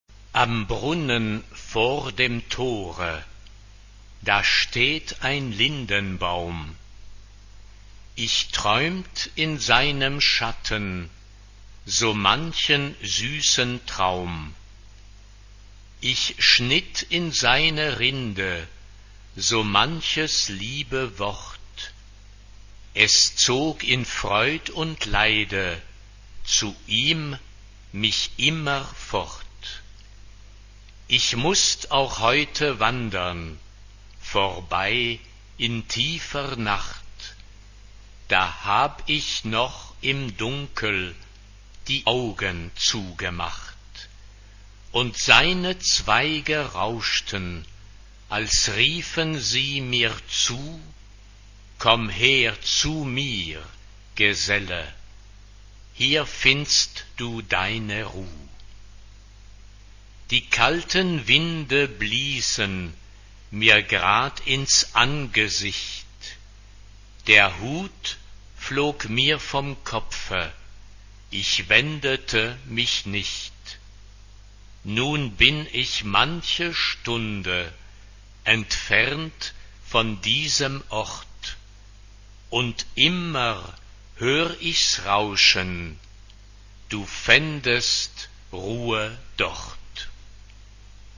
TTBB (4 voices men) ; Full score.
Lied. Romantic.